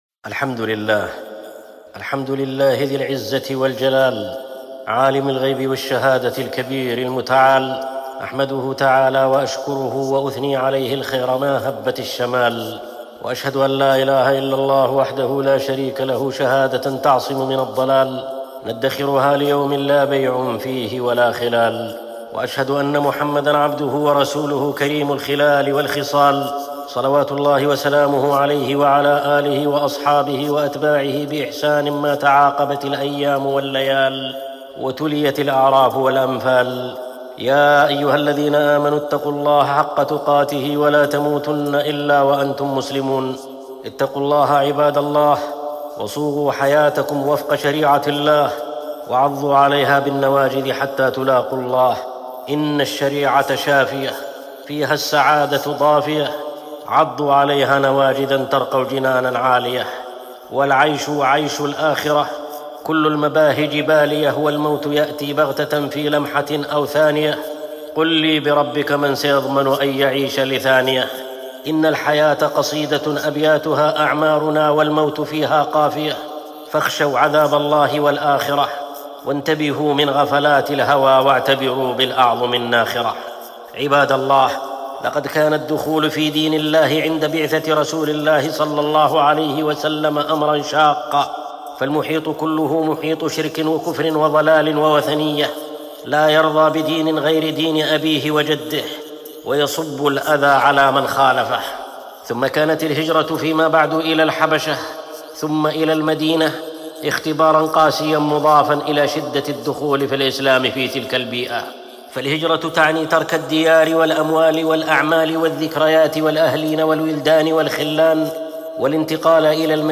أصل هذه المادة حطبة جمعة